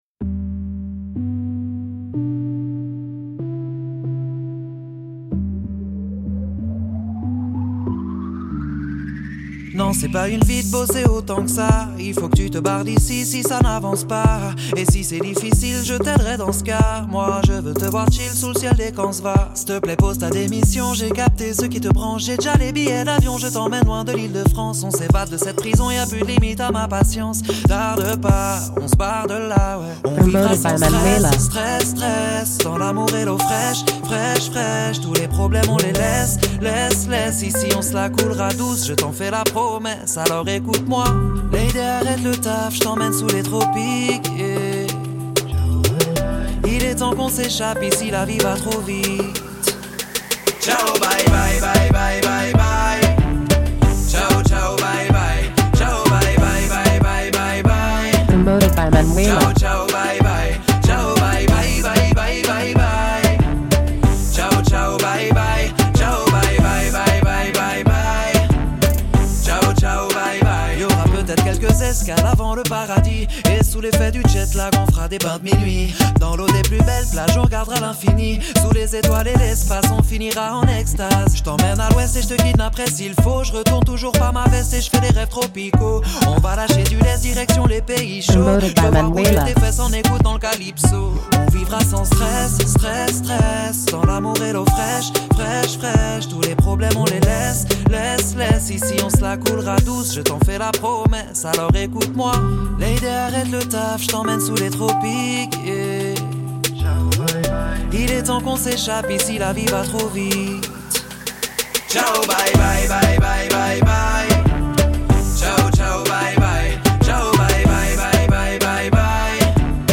Radio Edit
Légèreté, soleil et good vibes